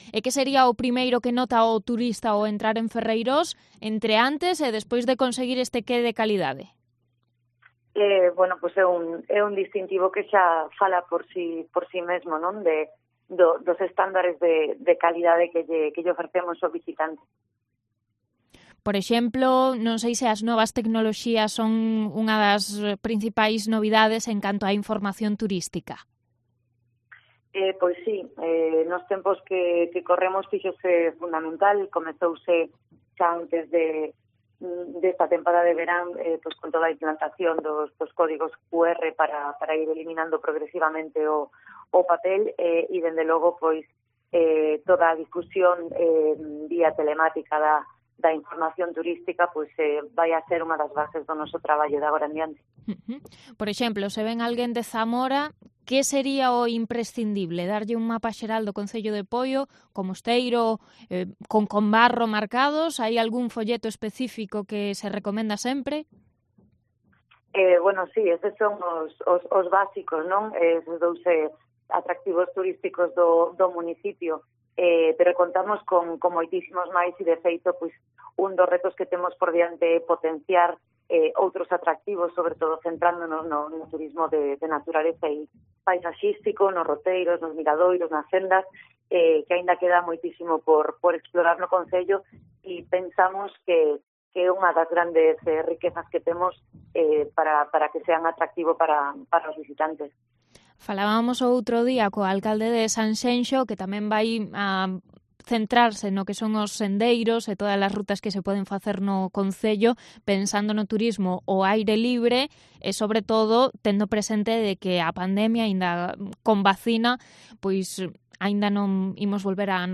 Entrevista a Silvia Díaz, concejala de Turismo de Poio